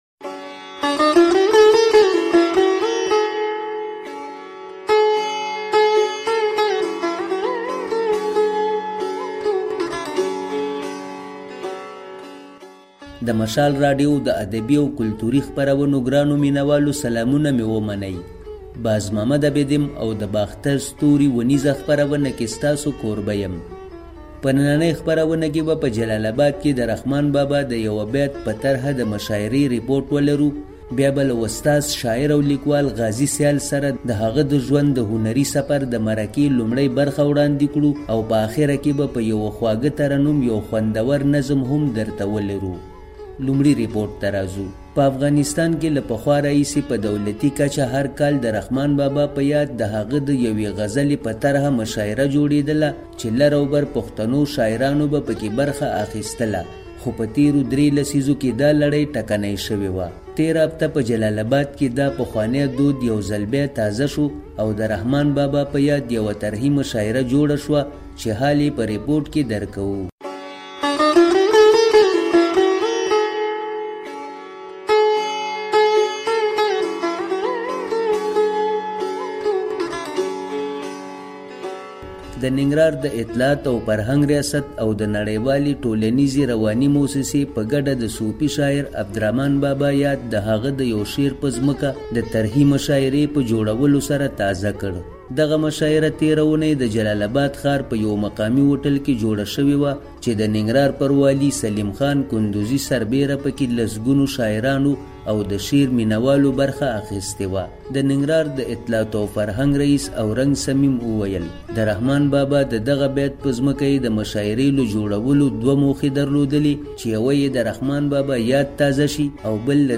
د رحمان بابا په يوه بیت د طرحې مشاعرې ريپوټ
په ترنم کې يو نظم ځای شوي دي